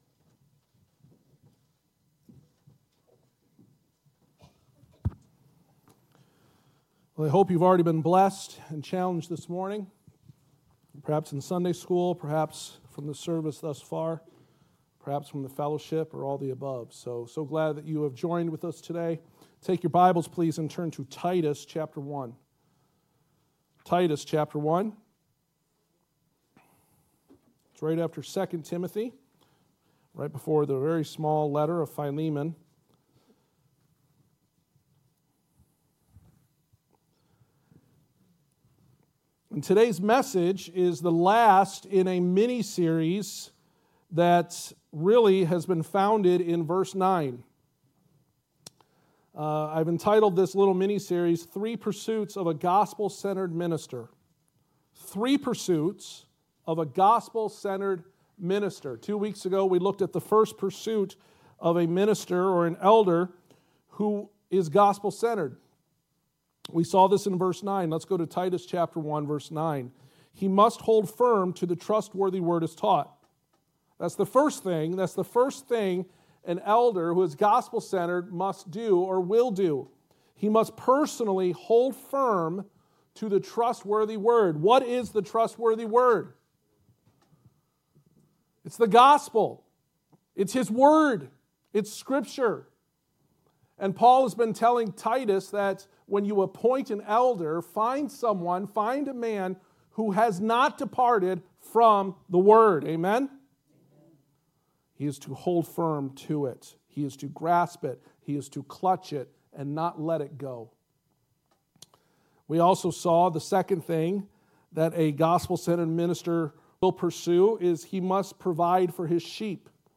Passage: Titus 1:9-16 Service Type: Sunday Morning